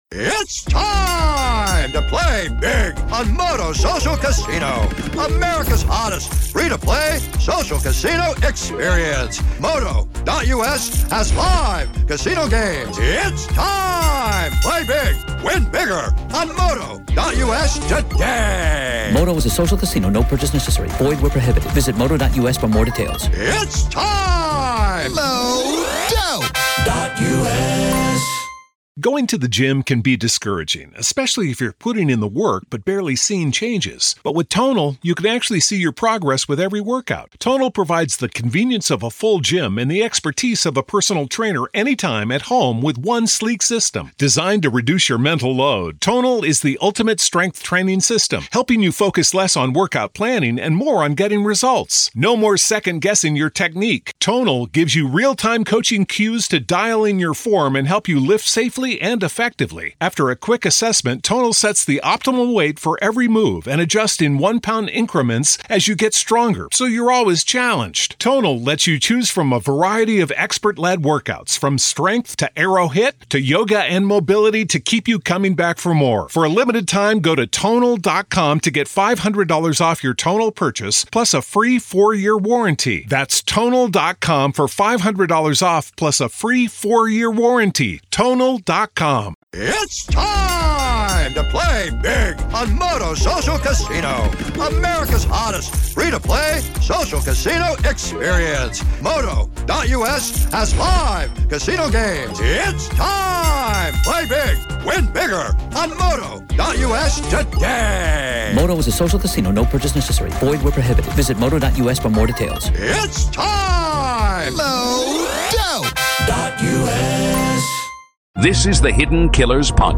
Bryan Kohberger Hearing Raw Court Audio: